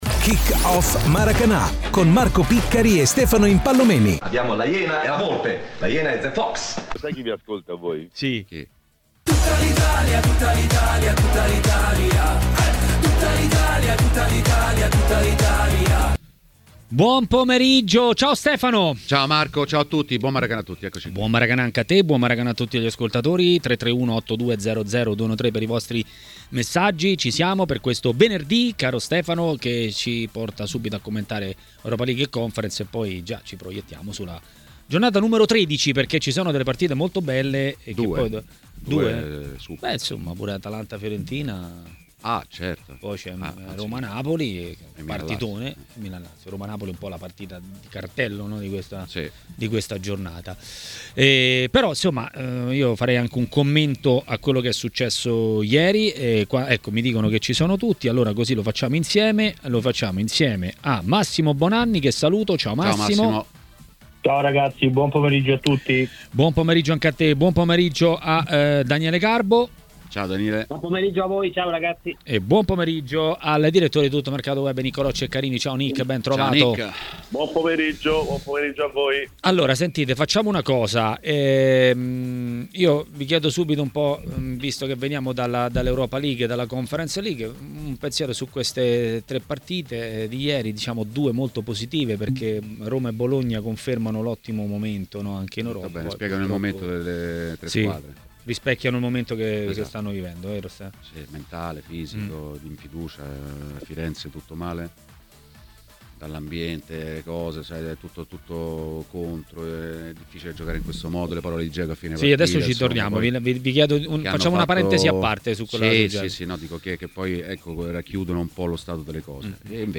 A Maracanà, nel pomeriggio di TMW Radio